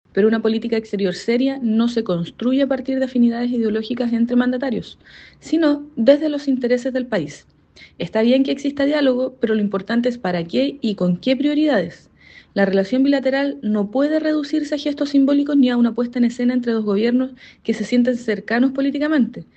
Frente a todo este escenario, la diputada Coca Ñanco (FA) fue crítica de la agenda, cuestionando el carácter ideológico de la visita y sosteniendo que Chile necesita una relación seria con el país vecino, que se construya en base a los verdaderos intereses de las naciones.